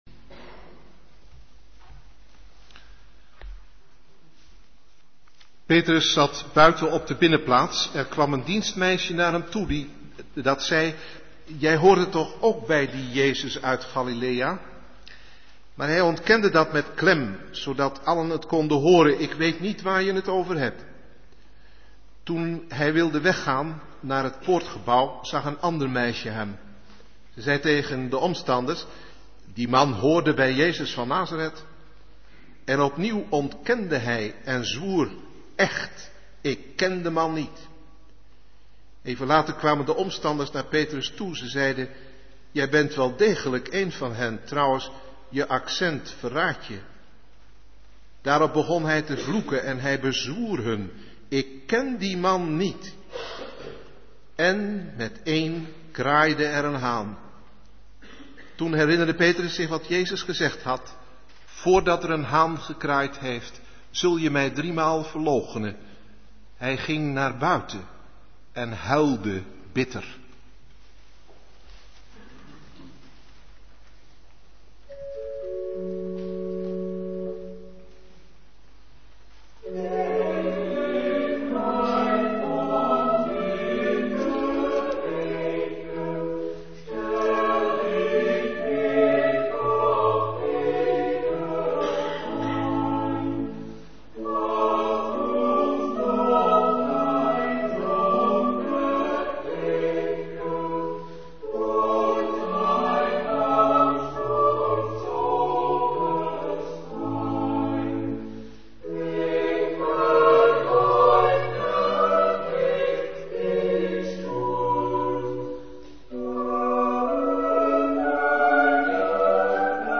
Uit de dienst van 18 Maart 2007: Lezing over Petrus verloochening
koorzang